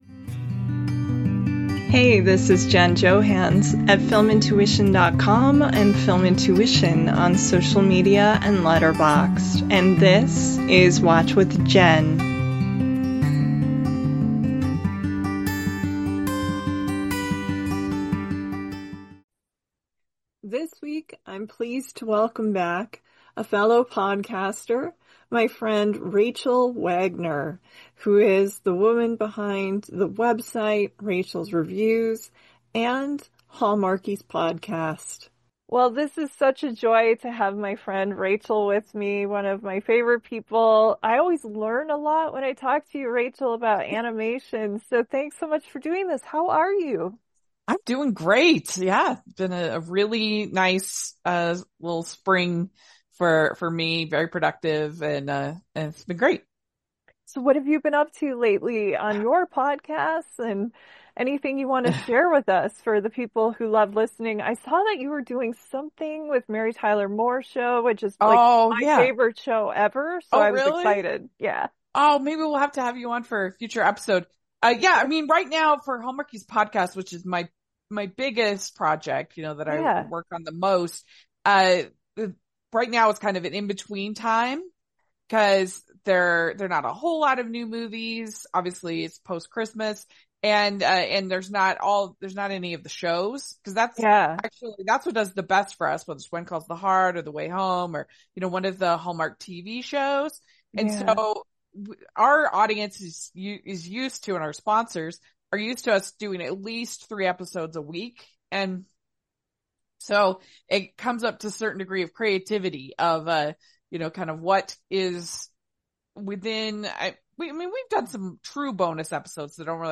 Joining me to discuss the fantastic range of character actor Robert Downey Jr. (whom she's dubbed Walking Charisma), in this thoughtful celebratory conversation, we analyze what it is that makes him so unique, and champion his work in the films ONLY YOU, KISS KISS BANG BANG, FUR: AN IMAGINARY PORTRAIT OF DIANE ARBUS, ZODIAC, & IRON MAN.